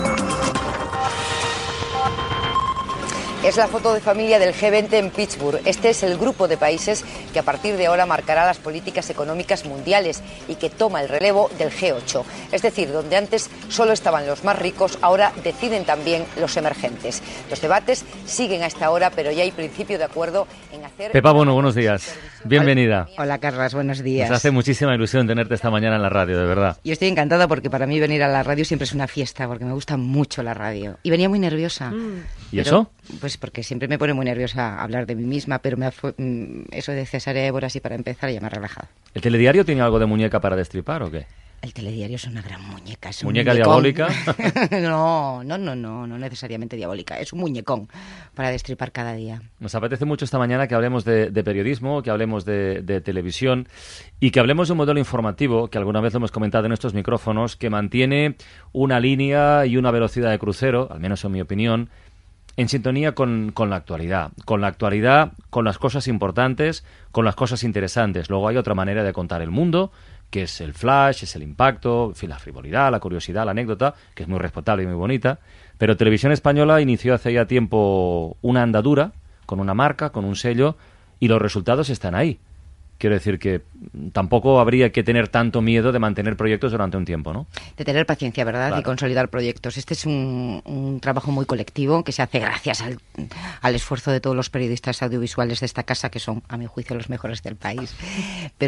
Entrevista a la periodista Pepa Bueno presentadora de la segon edició del Telediario de TVE
Info-entreteniment